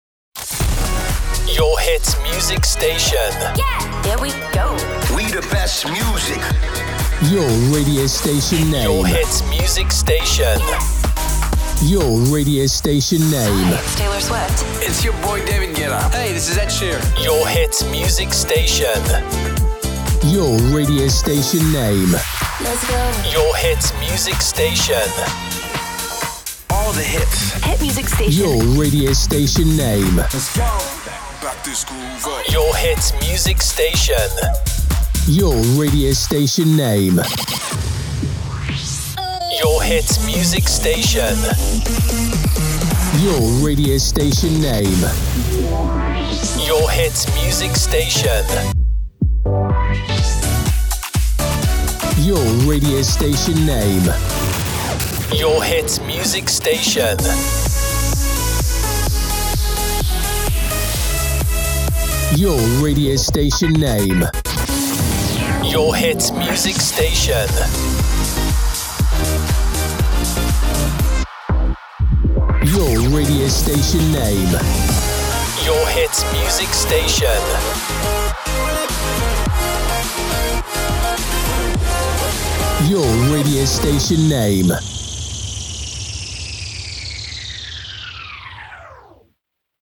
Version 1: Hit Music